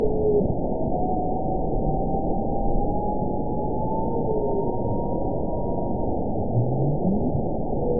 event 922176 date 12/27/24 time 23:13:07 GMT (11 months, 1 week ago) score 9.28 location TSS-AB04 detected by nrw target species NRW annotations +NRW Spectrogram: Frequency (kHz) vs. Time (s) audio not available .wav